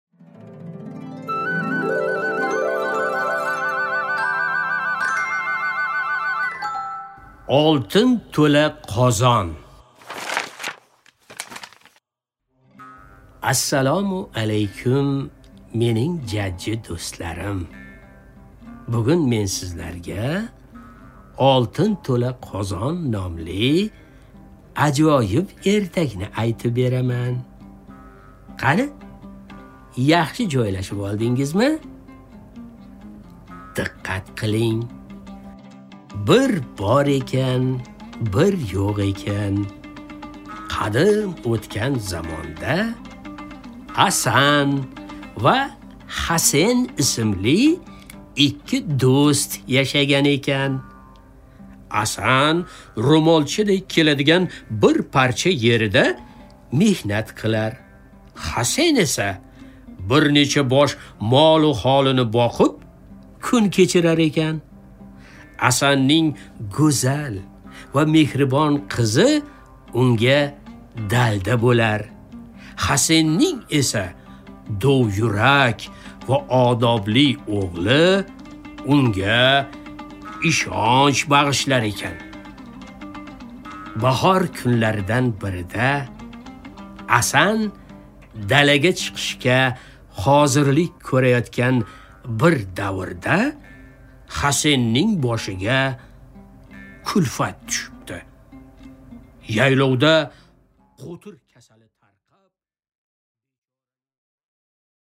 Аудиокнига Oltin to'la qozon